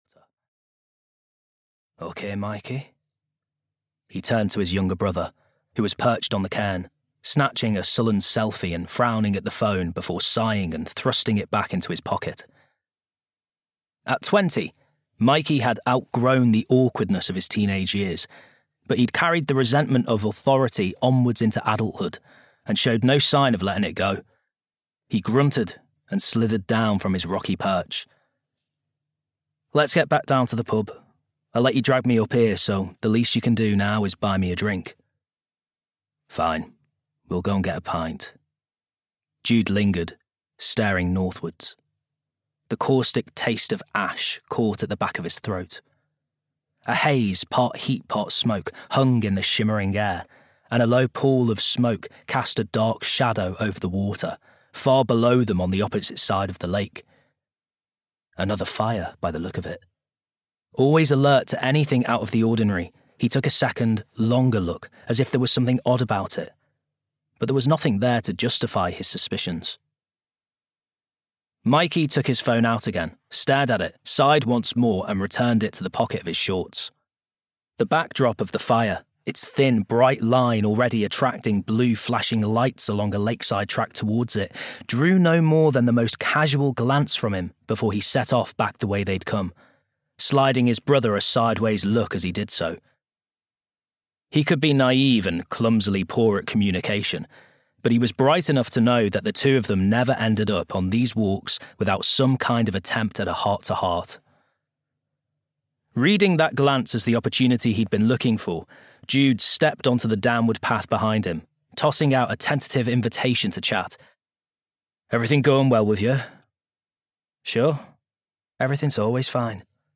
Death by Dark Waters (EN) audiokniha
Ukázka z knihy